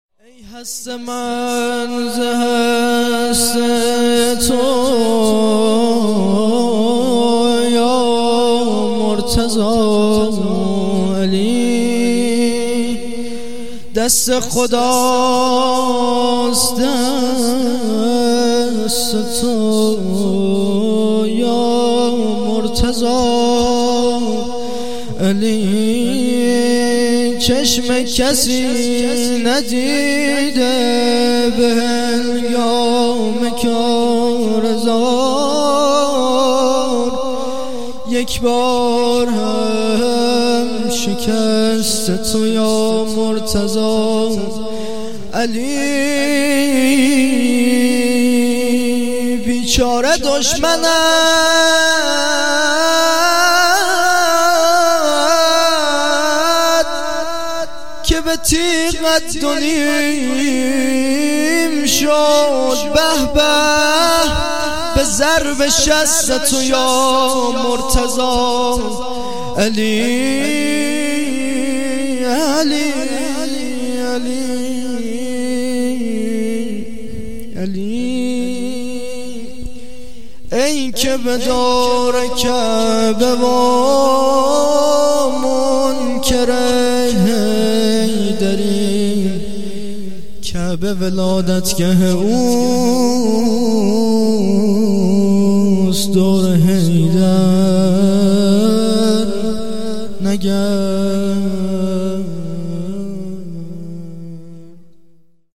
مدح
هیئت فرهنگی مذهبی فاطمیون درق